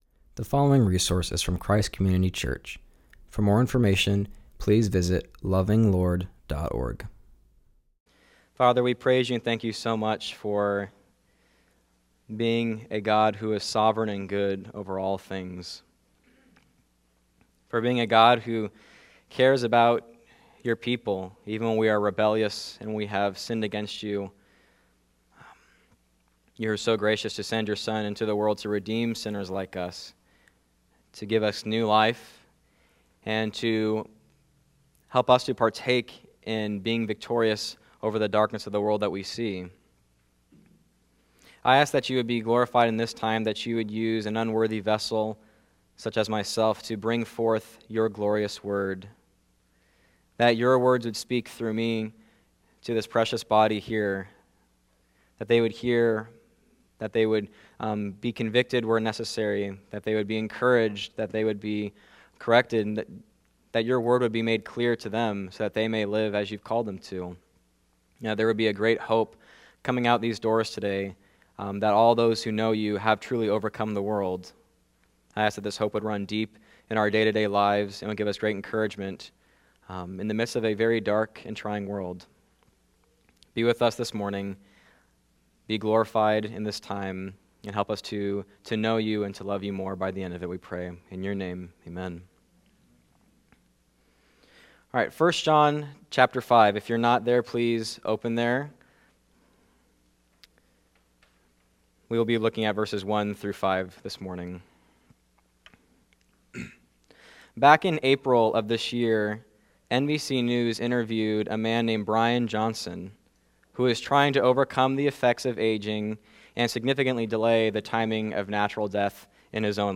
preaches from 1 John 5:1-5.